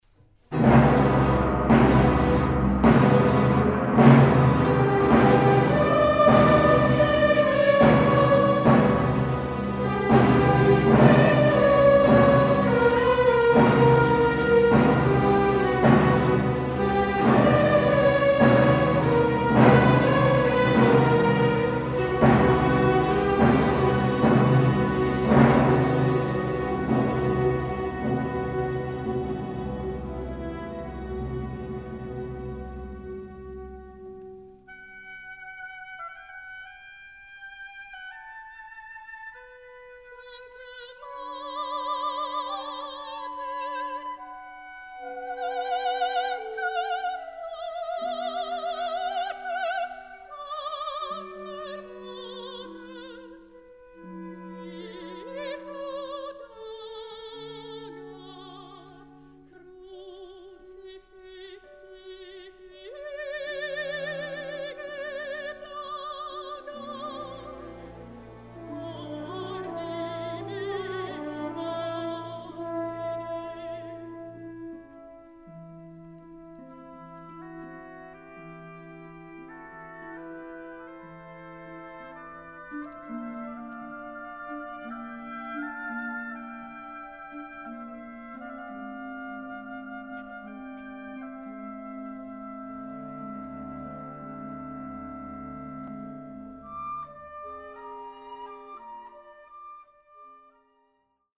1. Sopran Solo un Frauenchor T. 74-115: